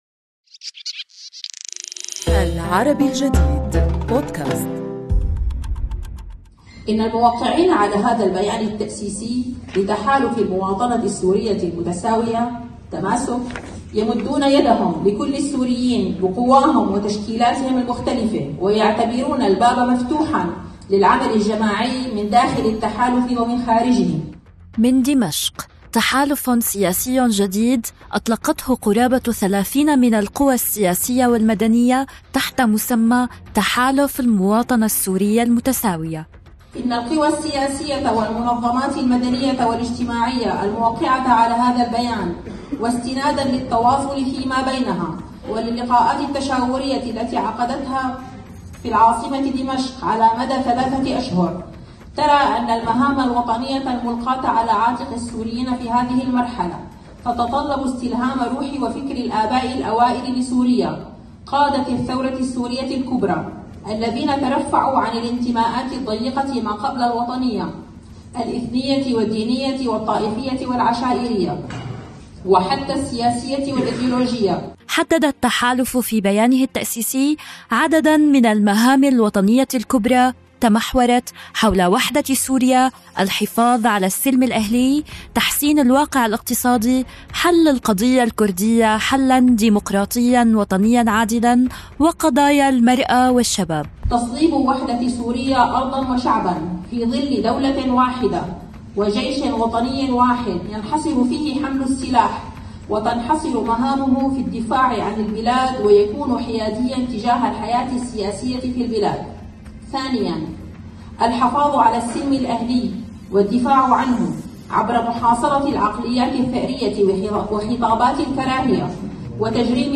في الثاني والعشرين من مارس/ آذار، من فندق الأرميتاج، كان لنا هذا الحديث مع ممثلين عن قوى مشاركة في التحالف الناشئ.